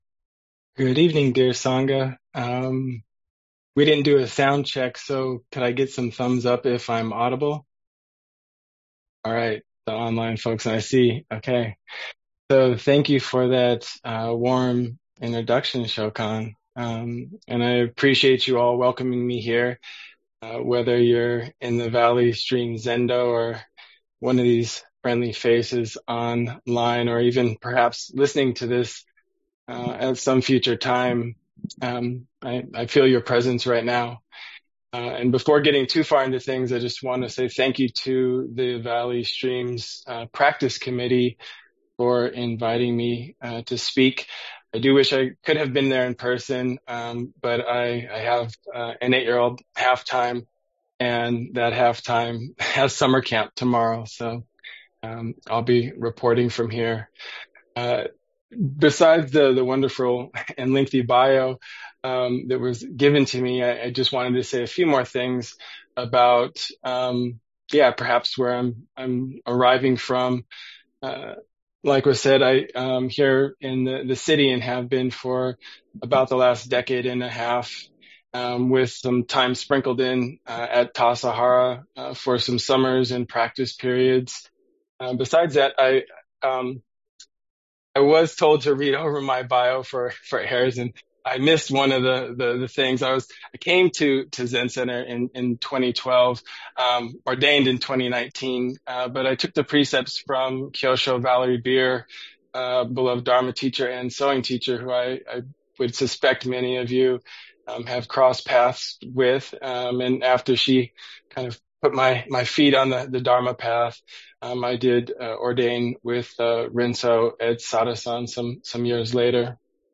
Dharma Talk given on Monday, July 21st, 2025